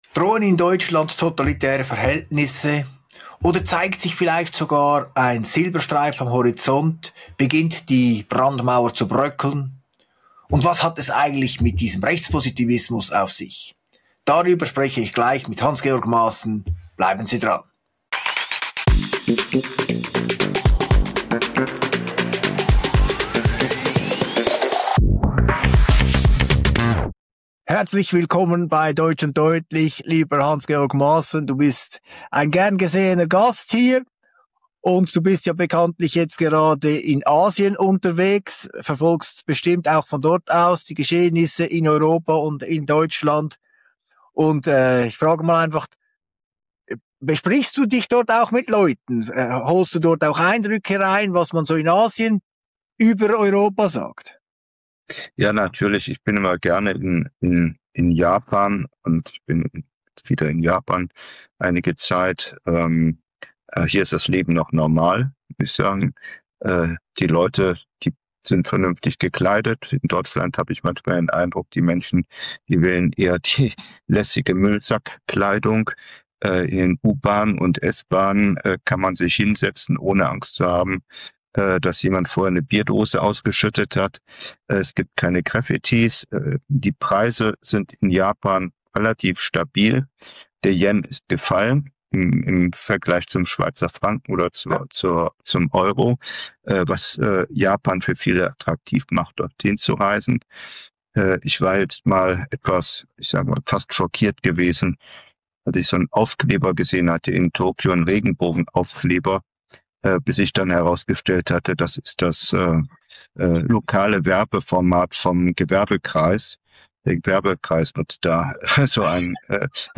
Im Gespräch mit Claudio Zanetti in der heutigen Folge «Deutsch und deutlich» berichtet Hans-Georg Maassen aus Japan, wo das Leben geordnet, sauber und normal wirkt – im Kontrast zu Deutschland mit Graffiti, Müllsack-Mode und instabilen Preisen.